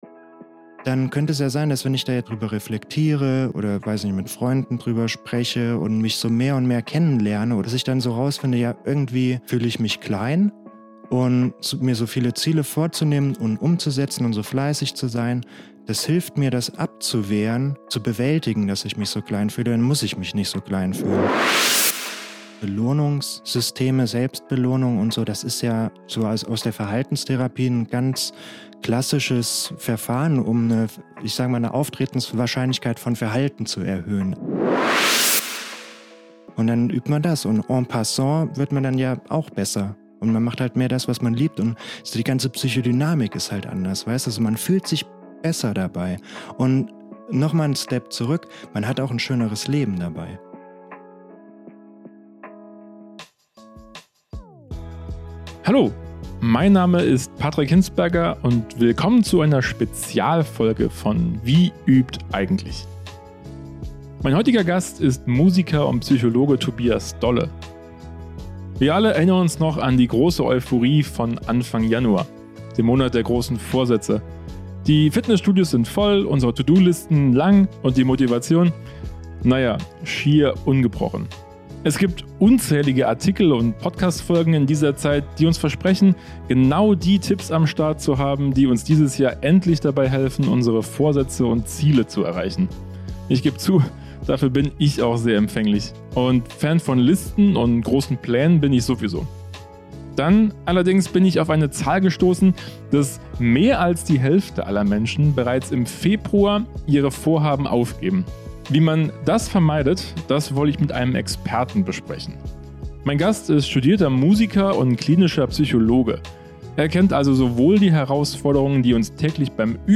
Wie man das vermeidet wollte ich also mit einem Experten besprechen.